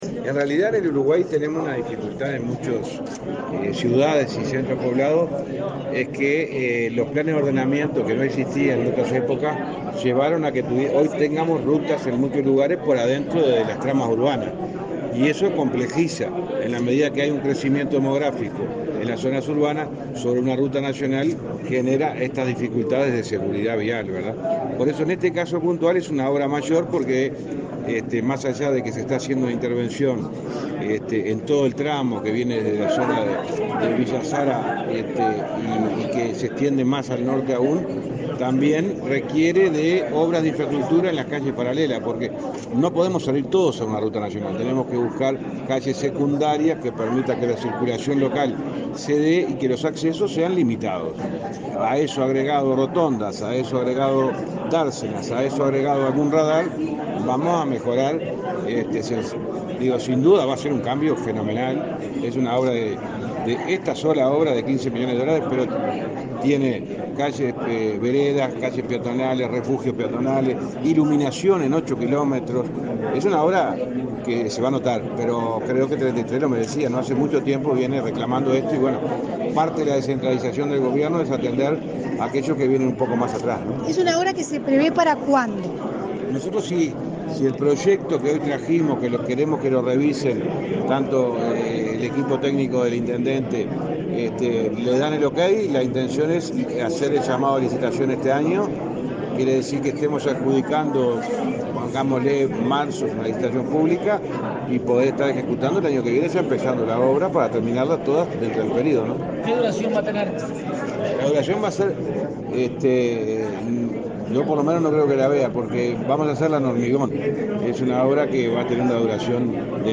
Declaraciones a la prensa del ministro de Transporte y Obras Públicas, José Luis Falero, en Treinta y Tres
Tras el evento, el ministro realizó declaraciones a la prensa.